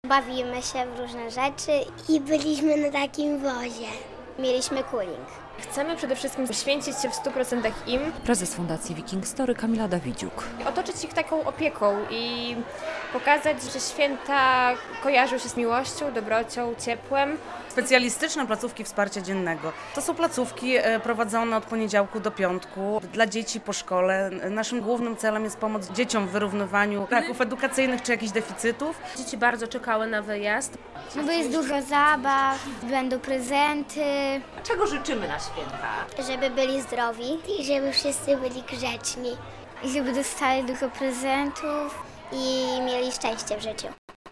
Podopieczni Prawosławnego Ośrodka Miłosierdzia "Eleos" bawili się w Majątku Howieny w Pomigaczach - relacja